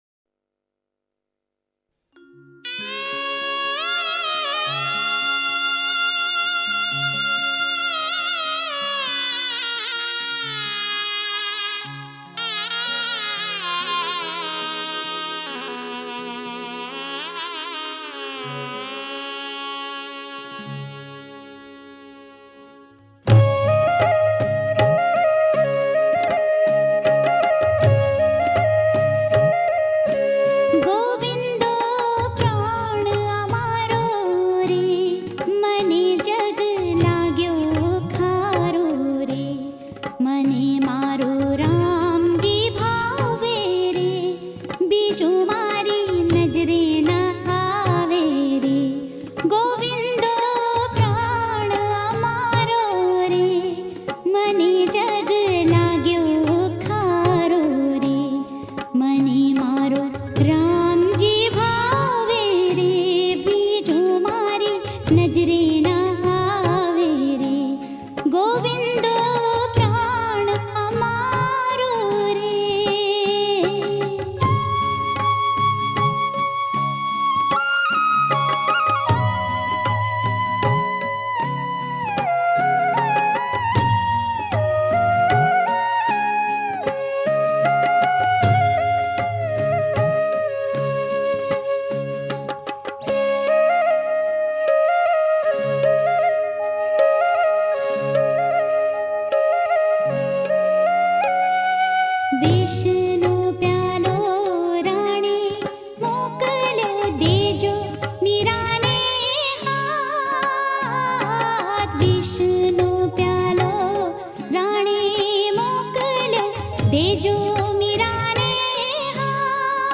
ગીત સંગીત ભજનાવલી - Bhajans